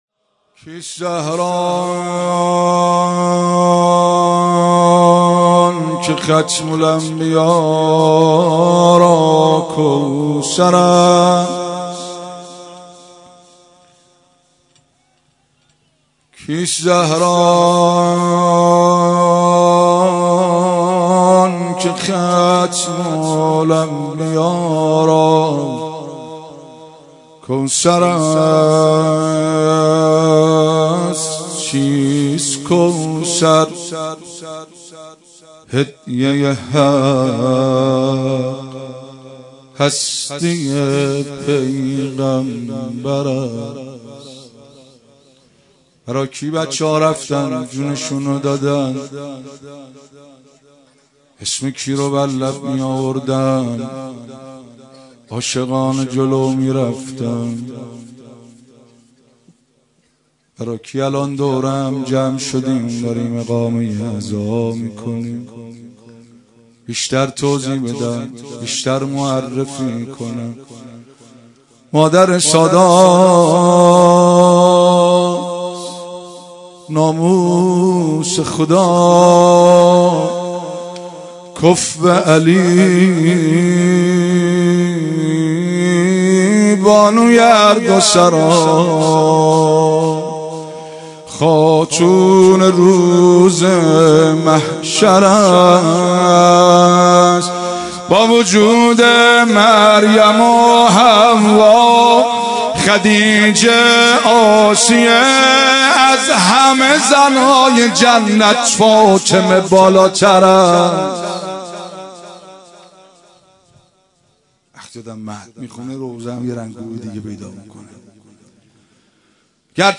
روضه و ذکر